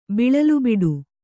♪ biḷalu biḍu